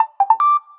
sms3.wav